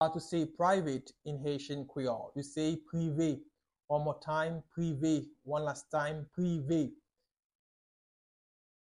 Pronunciation:
20.How-to-say-Private-in-Haitian-Creole-–-Prive-with-pronunciation.mp3